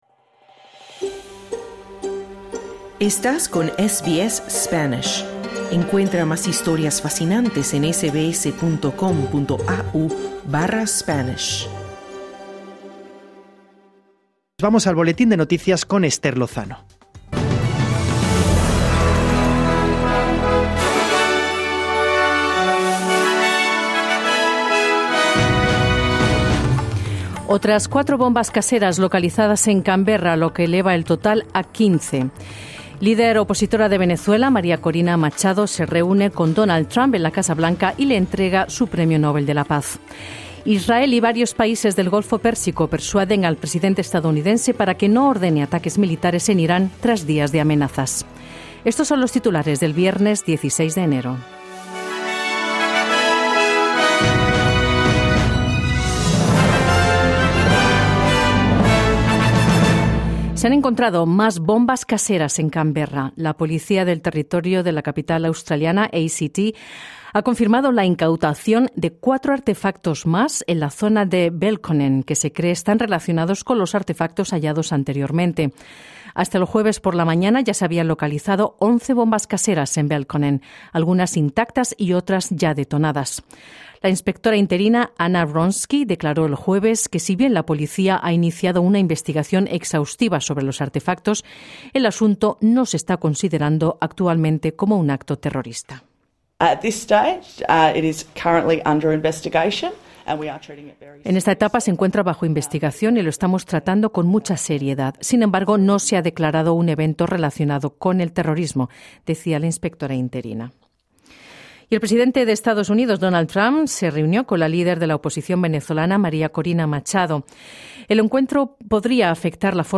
Boletín de noticias viernes 16/1/2026: Otras cuatro bombas caseras son localizadas en Canberra, lo que eleva el total a 15 artefactos. La líder opositora de Venezuela, María Corina Machado se reúne con Donald Trump en la Casa Blanca y le entrega su premio Nóbel de la Paz.